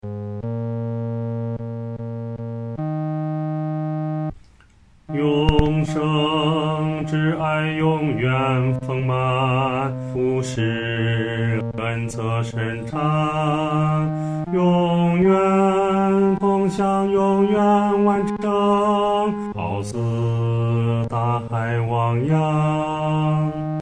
男低